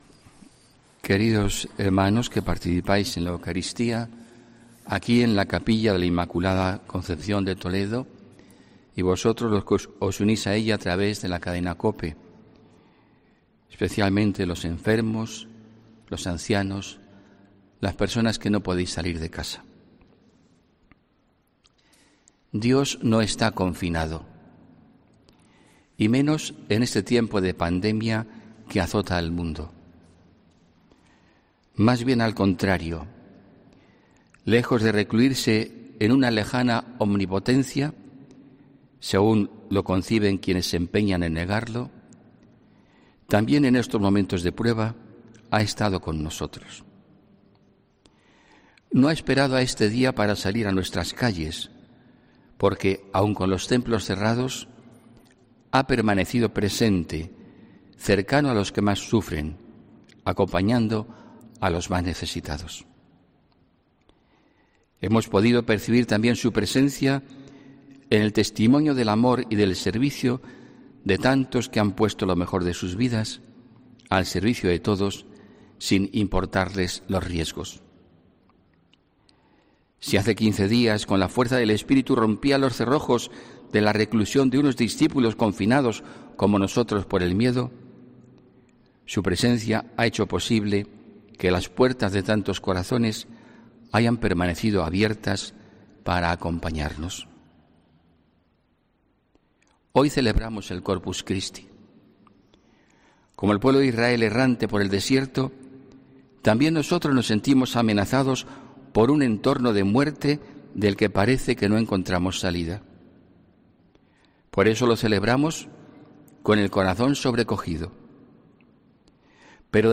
HOMILÍA 14 JUNIO 2020